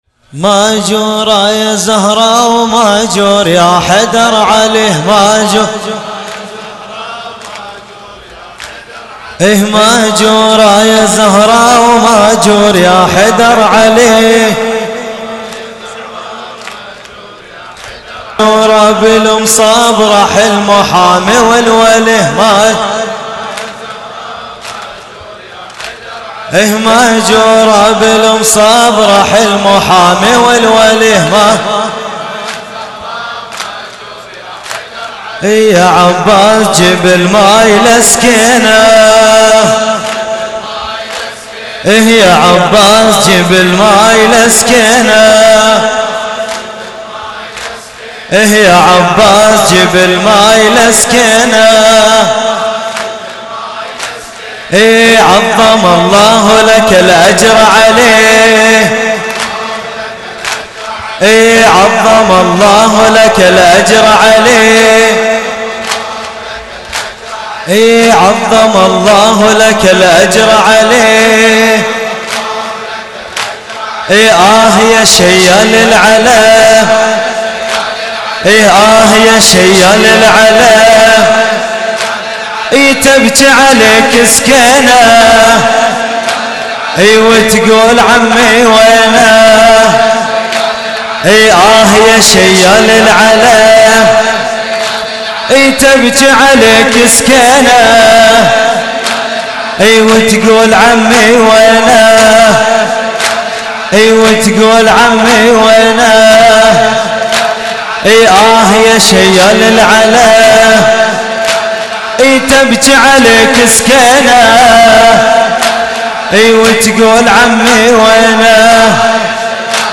لطمية الليلـ 07 ـة الجزء (01)